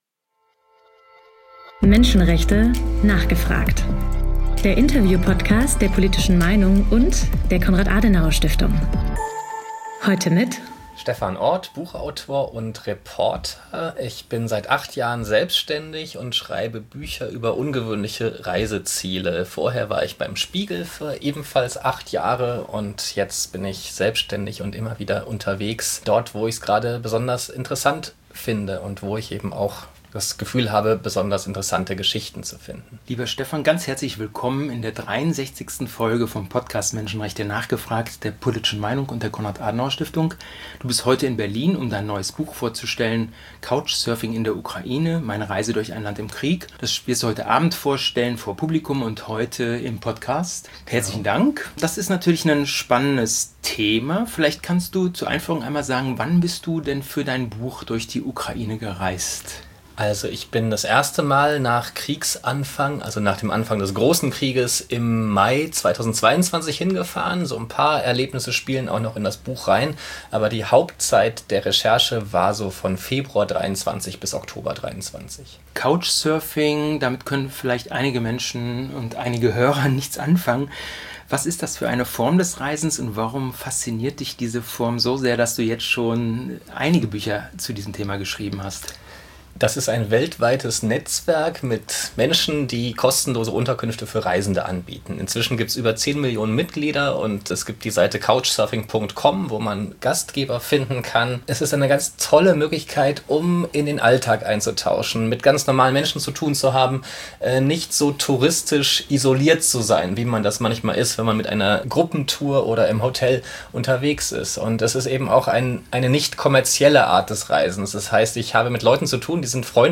Meine Reise durch ein Land im Krieg mit Stephan Orth, Journalist - Menschenrechte: nachgefragt! - Der Interview-Podcast rund ums Thema Menschenrechte